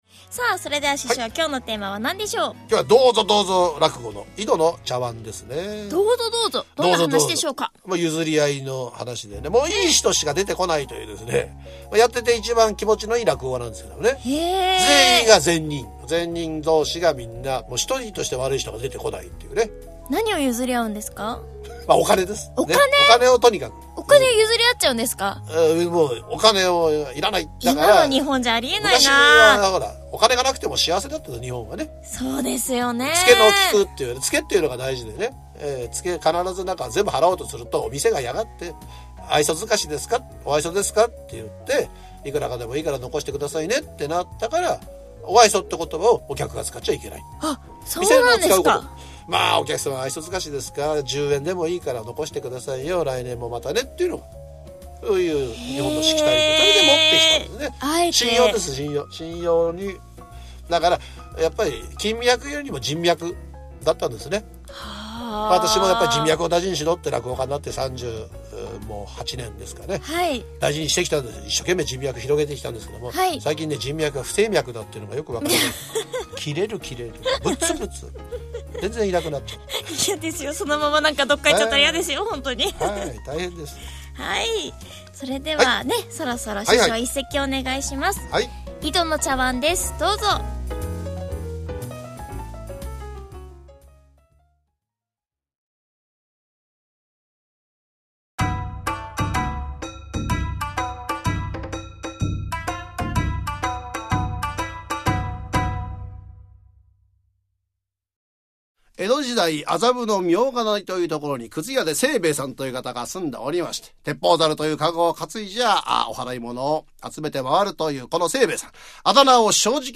そんなあなたのための、ゆるーいテイストの落語ラジオコンテンツを録りました。
AMラジオのノリで、ざっくばらんに落語にまつわる疑問質問を失礼覚悟で師匠にぶつけてみました。
しかし返って来るのはザッツ楽屋トーク。
毎回テーマに沿ったオープニングトークと、テーマに沿った？古典落語の名作を一席聴くことができます。